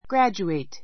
graduate A2 ɡrǽdʒueit グ ラ ヂュエイ ト 動詞 卒業する ⦣ 英国では大学だけに使い, 米国では大学以外の学校の場合にもいう. graduate from college [high school] graduate from college [high school] 大学[高校]を卒業する He graduated from Yale.